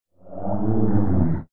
Глухие шаги, рычание, эхо пещер – все для погружения в атмосферу мифа.
Звук механического циклопа